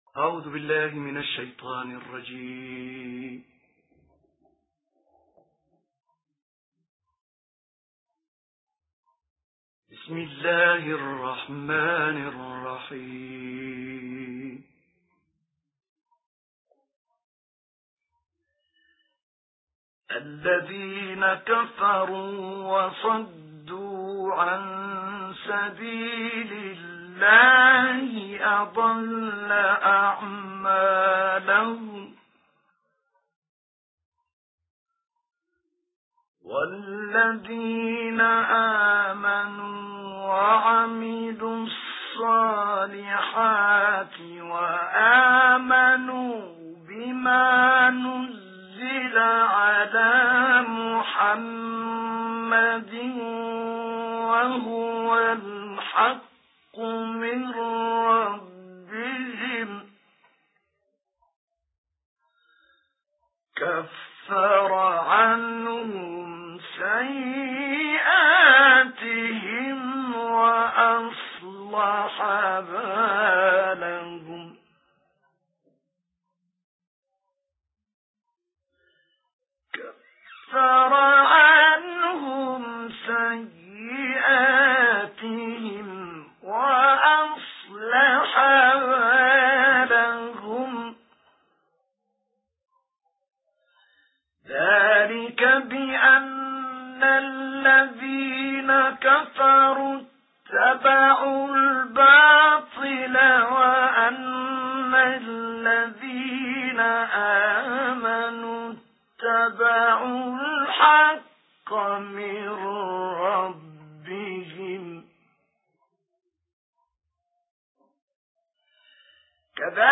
دانلود قرائت سوره محمد آیات 1 تا 19 - استاد طه الفشنی
قرائت-سوره-محمد-آیات-1-تا-19-استاد-طه-الفشنی.mp3